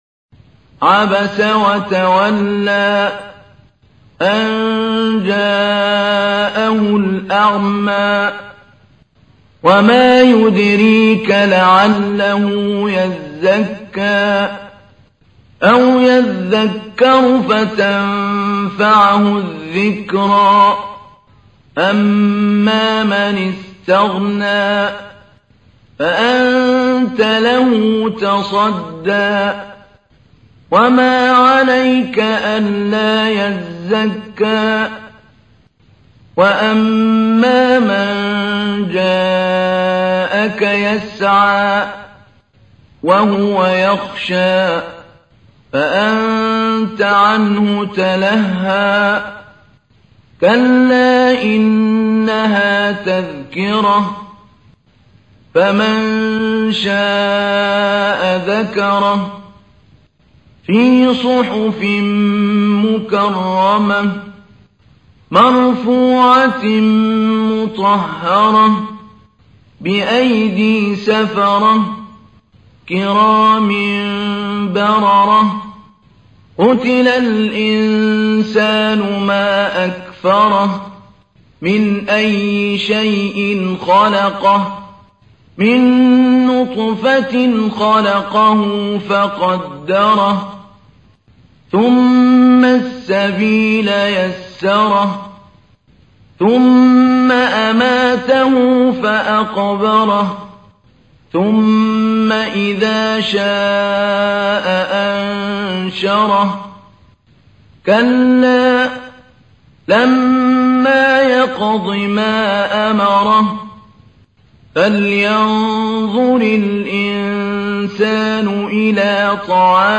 تحميل : 80. سورة عبس / القارئ محمود علي البنا / القرآن الكريم / موقع يا حسين